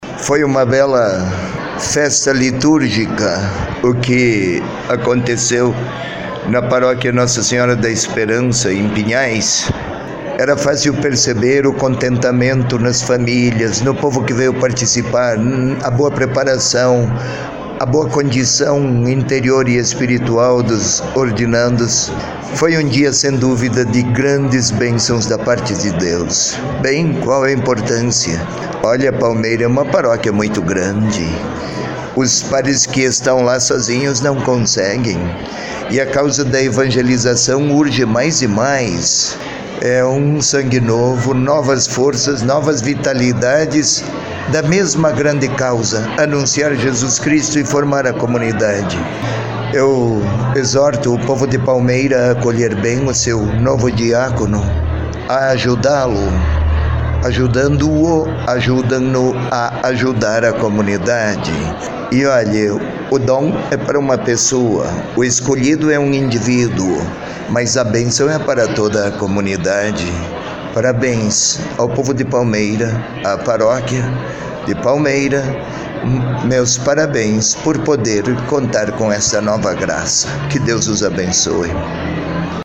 Ao final da celebração, os novos Diáconos foram cumprimentados pelos seus familiares e amigos, demais Diáconos e sacerdotes presentes. Dom José Antônio Peruzzo falou sobre a Graça que a Paróquia Nossa Senhora da Conceição recebeu.